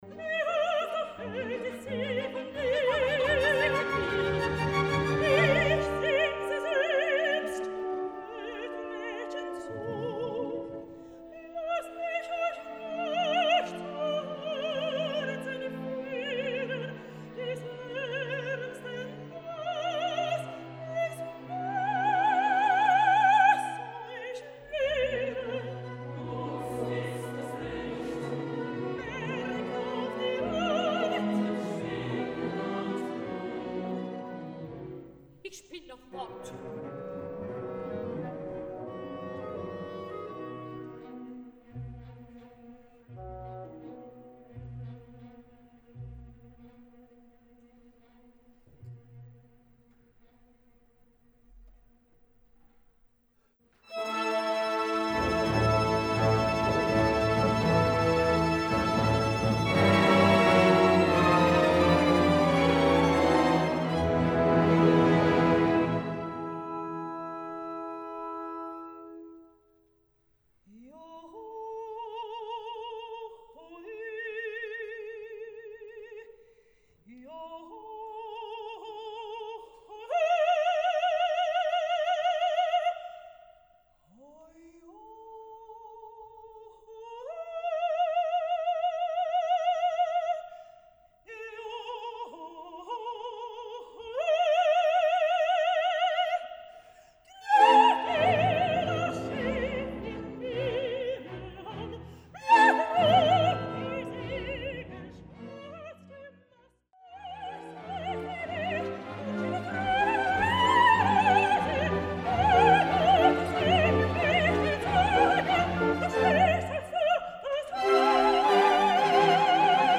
初稿の特徴としてはこの間の「救済なし」という点とともに、「第２幕」（と言っても、初稿では「幕」は下りず、続けて演奏されます）で歌われる「ゼンタのバラード」がイ短調で作られていた、という点が挙げられます。
それぞれにバラードが始まる少し前から始まり、バラードは途中をカット、そして最後から次のシーンに変わるとことまでになっています。